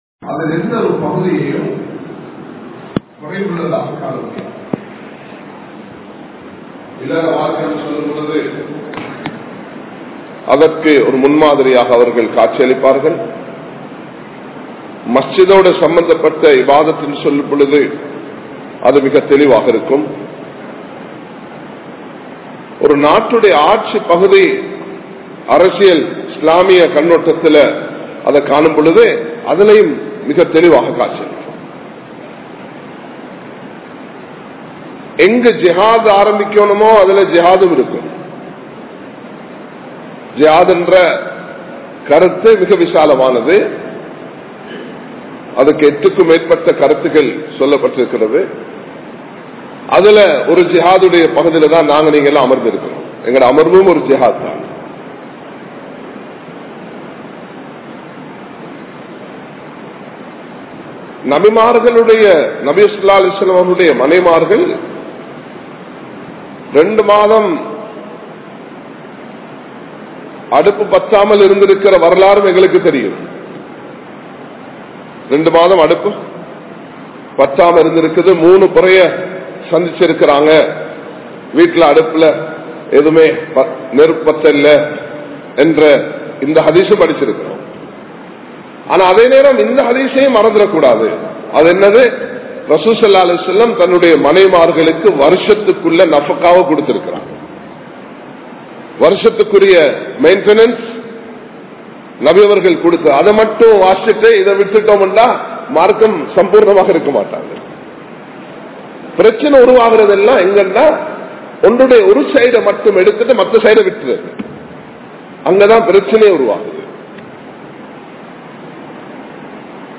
Saha Vaalvu(Coexistence) | Audio Bayans | All Ceylon Muslim Youth Community | Addalaichenai
Dehiwela, Muhideen (Markaz) Jumua Masjith